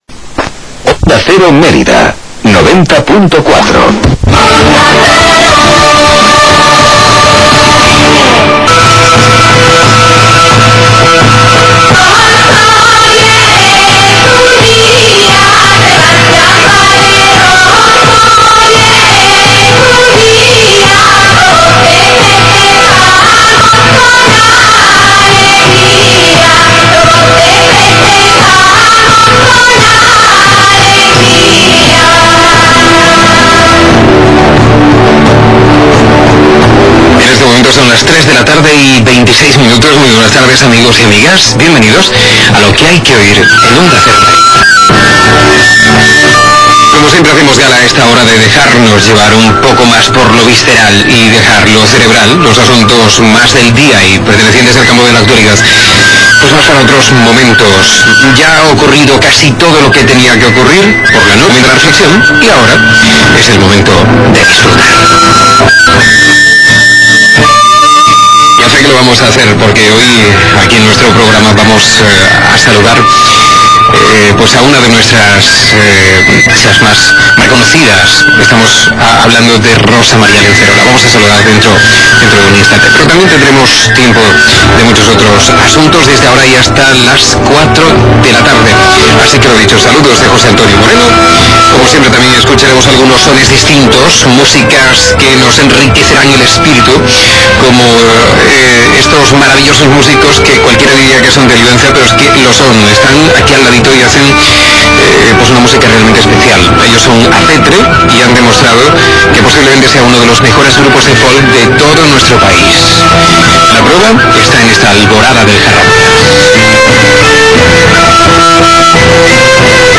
El grupo Acetre interpreta
Sintonía: Onda Cero